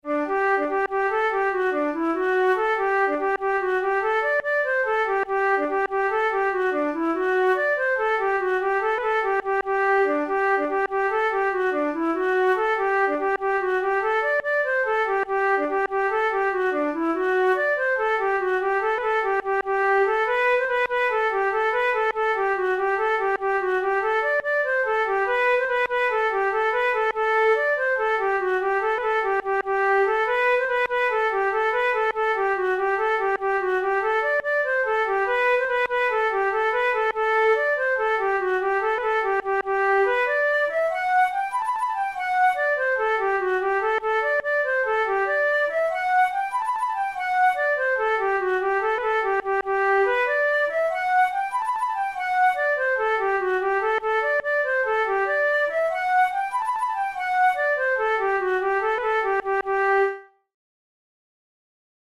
InstrumentationFlute solo
KeyG major
Time signature6/8
Tempo96 BPM
Jigs, Traditional/Folk
Traditional Irish jig